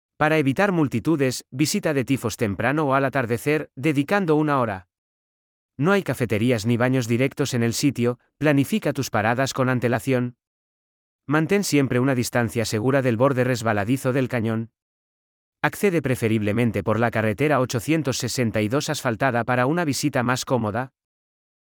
🎧 Guías de audio disponibles (2) Guía de Experiencia Emocional (ES) 🔗 Abrir en una nueva pestaña Información práctica (ES) 🔗 Abrir en una nueva pestaña